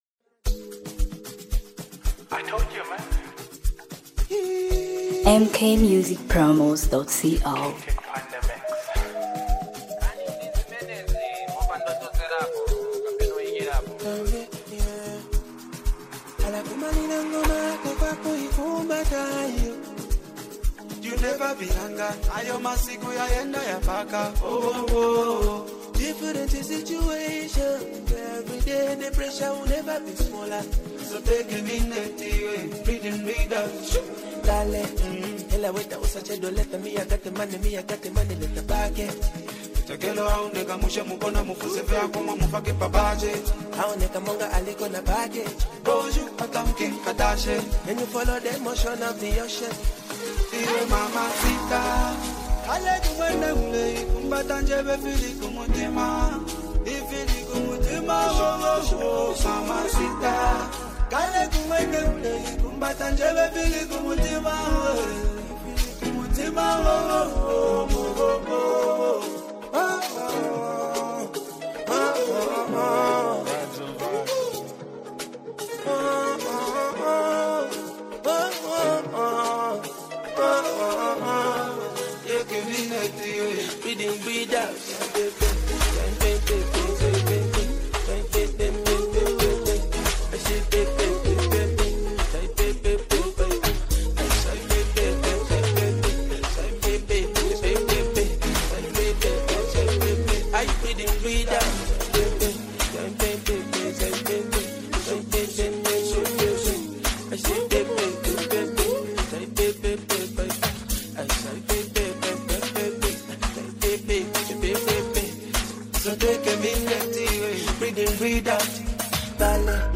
giving it a clean and modern Afro-pop sound.